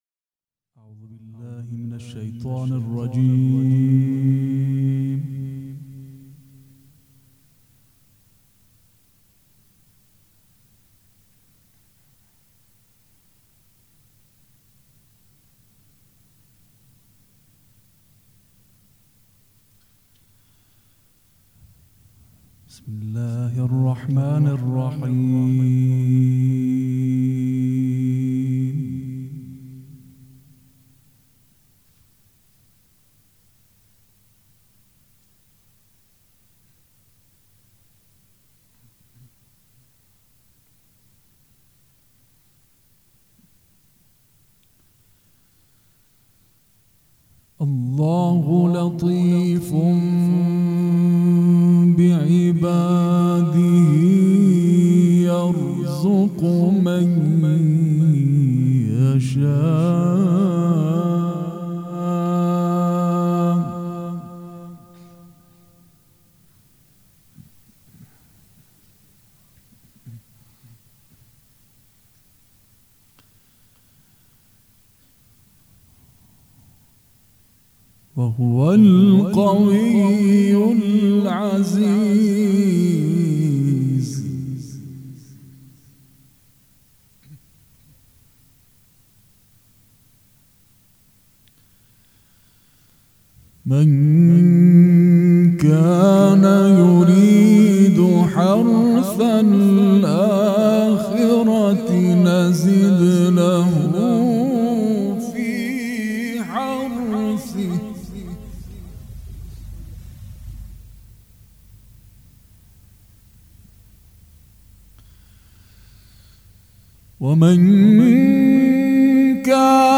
قرائت قرآن
قرائت قرآن کریم
شهادت امام صادق (ع)